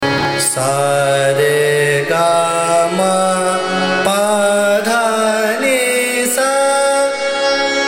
Raga
ArohaS r g m P d n S’
Bhairavi (Aroha)